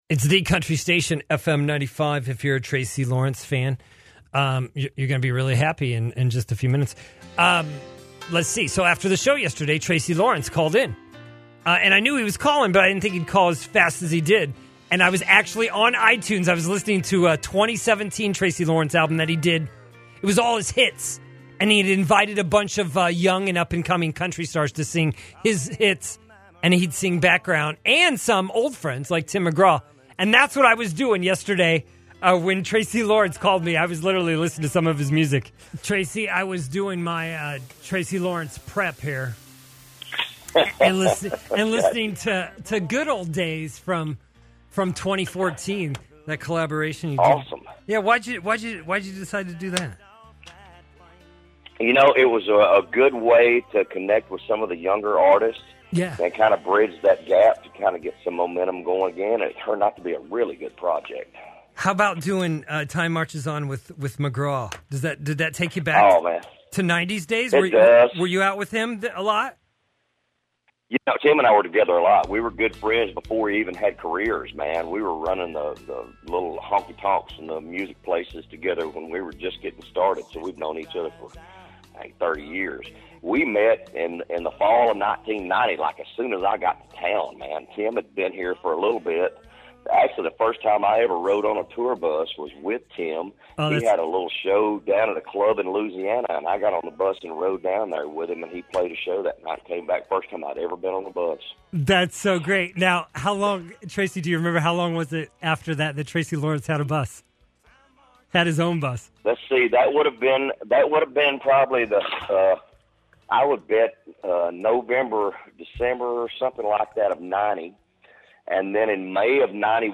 Tracy Lawrence Called The Morning Show on 5/1/20
Tracy Lawrence thrived on country radio big time in the 90’s and he’s back with an album he say’s he’s more proud of then almost any of his stuff. Tracy got nostalgic about his buddy Tim McGraw & an old Alabama hand-me-down tour bus. Check out our interview with Tracy below.